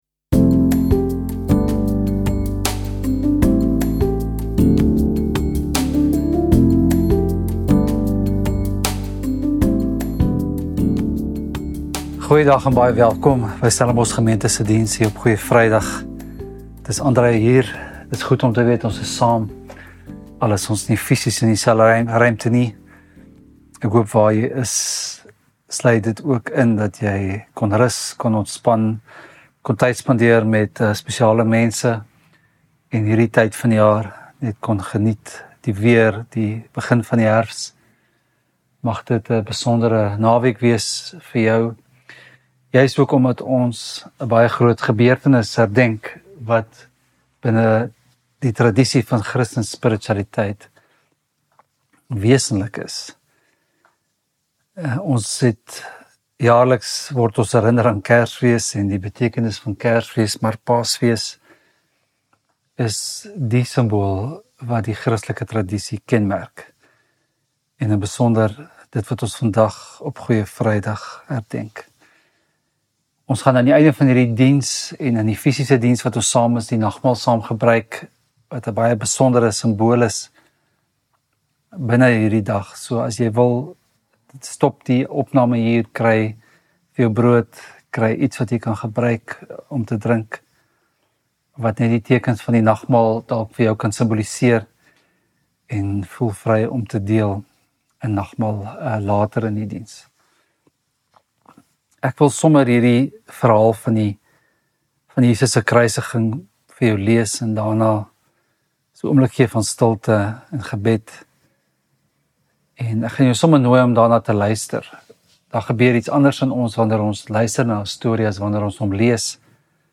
Preek | Goeie Vrydag